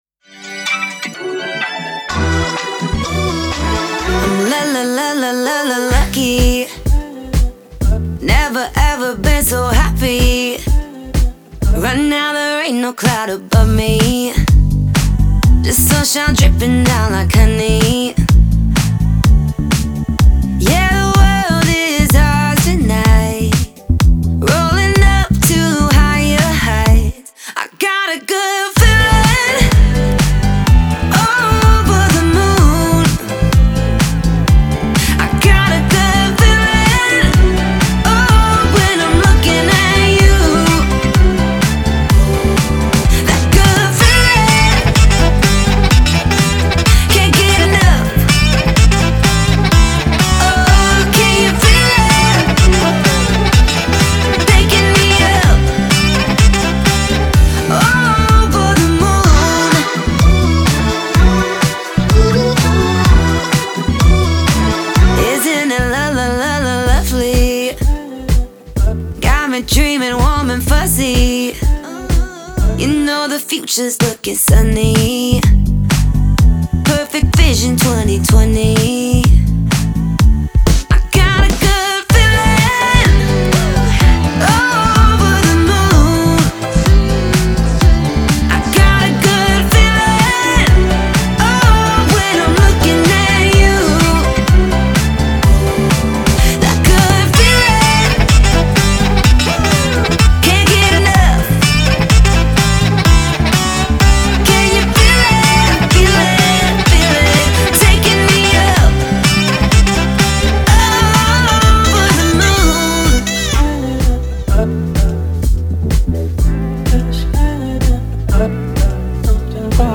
Synthesizer